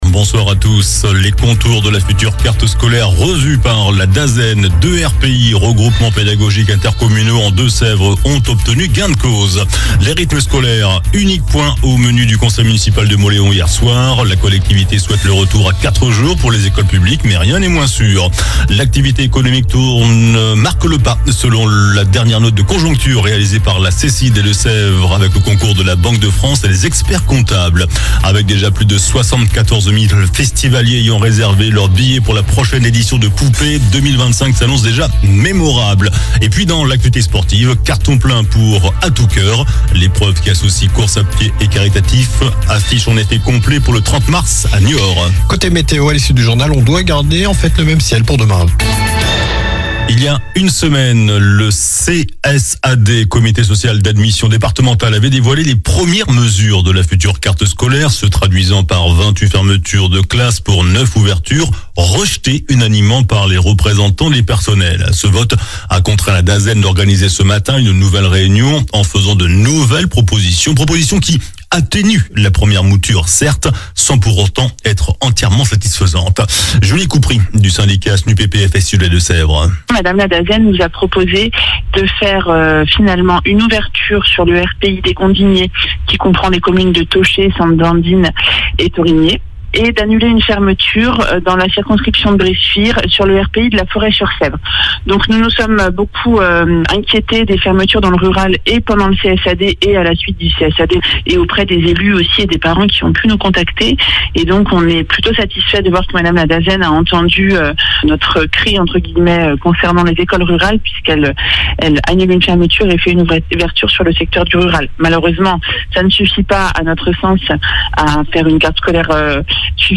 JOURNAL DU JEUDI 20 FEVRIER ( SOIR )